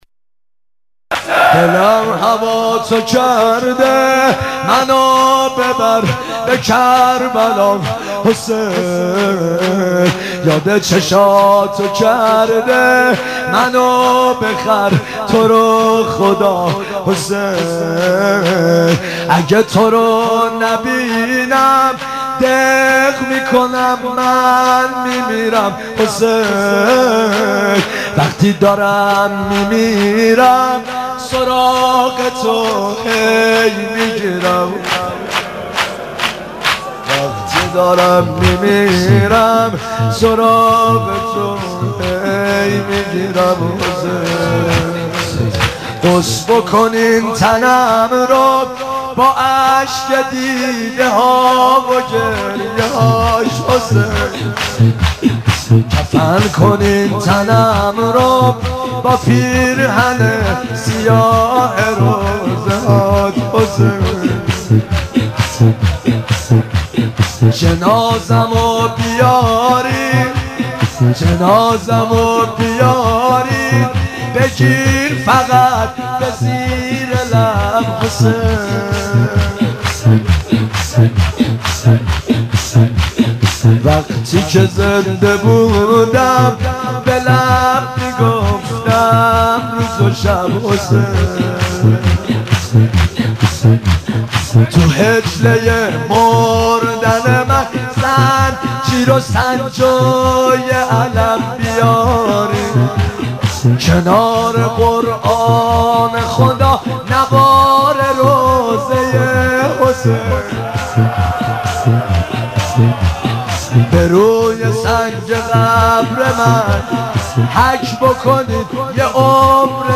نوحه مداحی کربلا مذهبی مرثیه دیدگاه‌ها